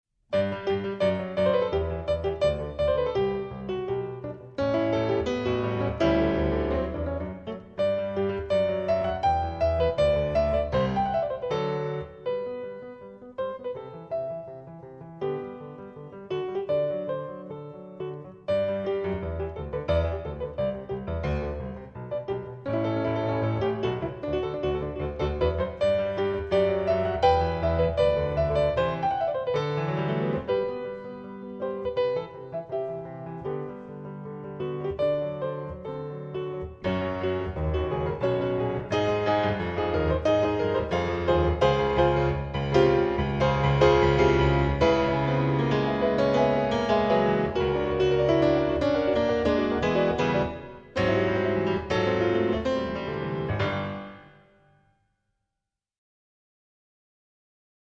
mezzo di esecuzione: pianoforte